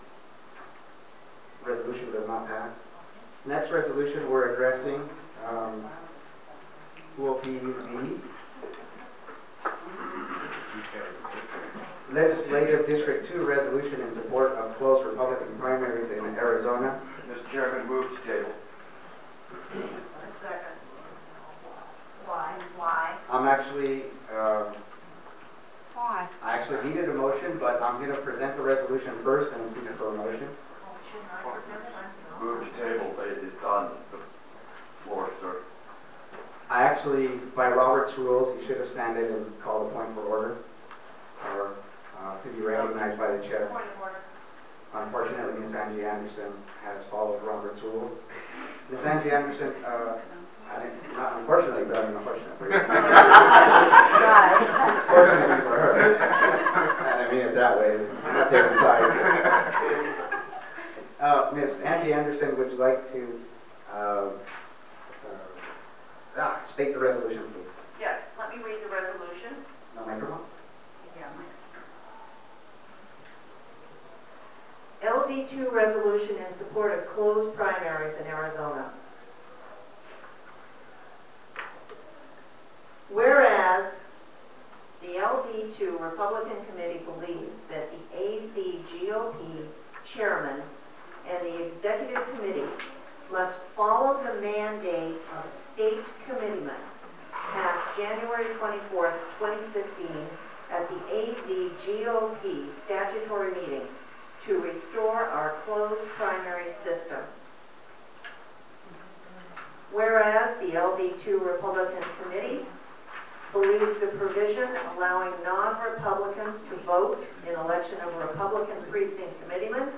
The specter of John McCain was present at a recent meeting the Arizona’s Legislative District 2, as Precinct Committeemen moved to pass a resolution to close the Republican primaries. Despite his operatives’ best efforts the resolution vehemently opposed by McCain passed.
Close-primary-LD2-Meeting.mp3